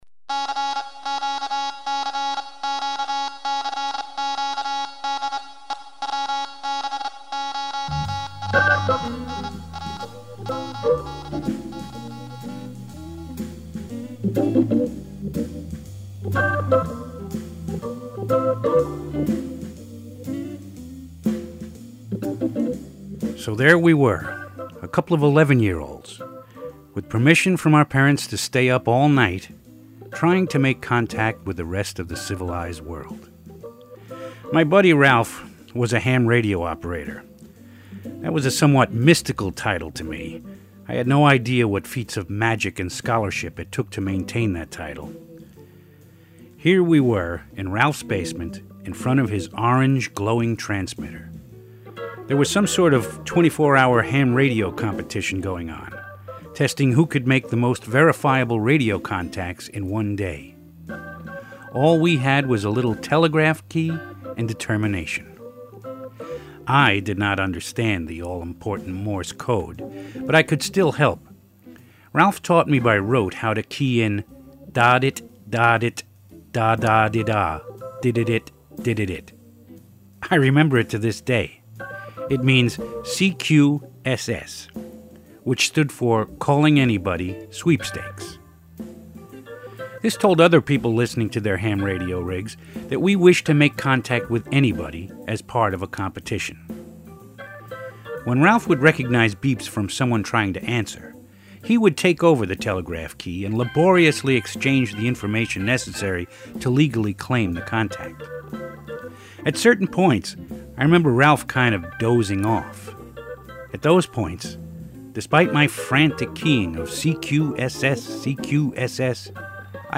We are proud to offer these great spoken word pieces again.